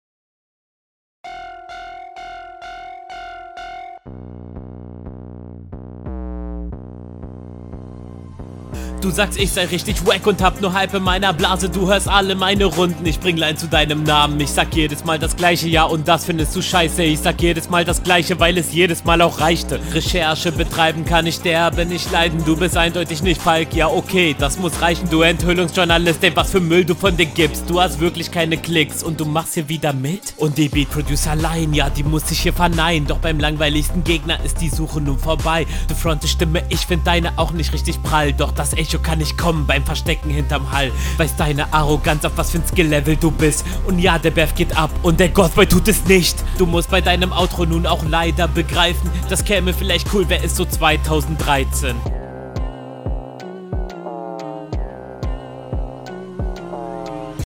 klingt wie der routiinierte rapper, der aber während der nachtruhe aufnehmen musste, also flow draw. …
Stimme kommt auf dem Beat leider nicht so gut, hättest du ggf. etwas abmischen können.